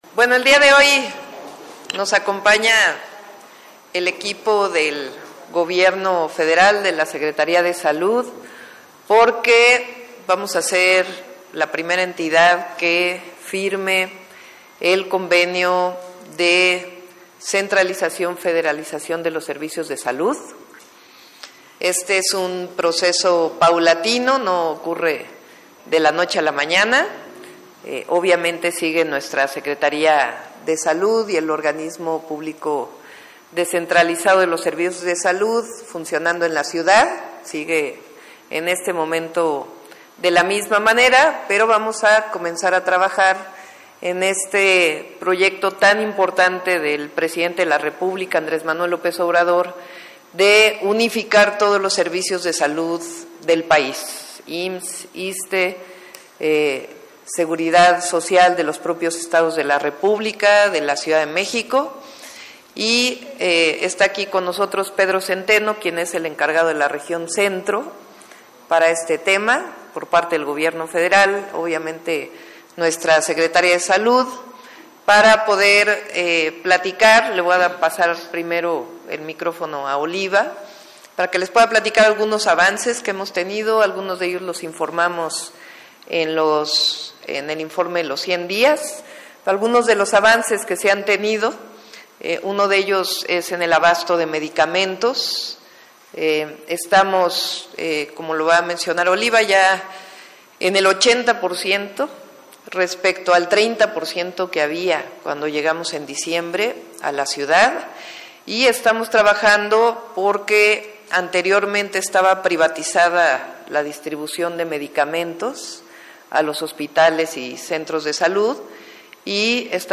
MENSAJES-CONFERENCIA-DE-PRENSA.mp3